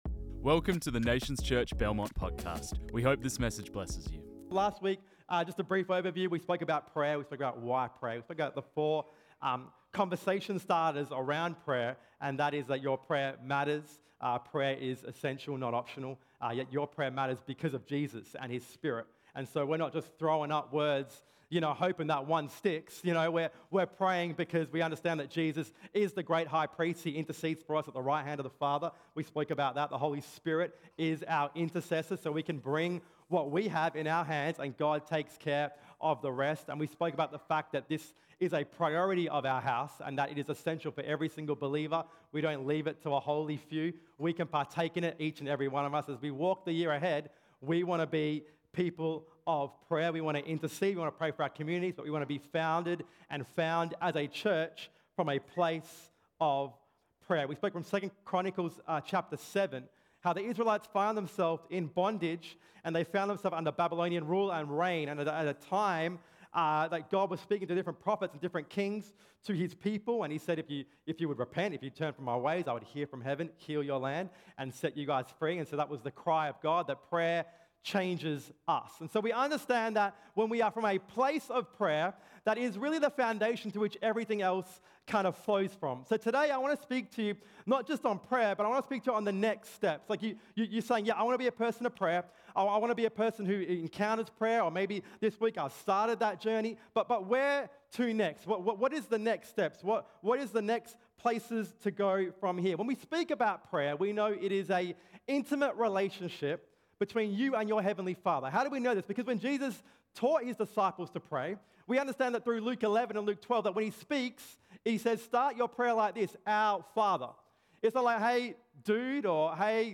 This message was preached on 4 February 2024.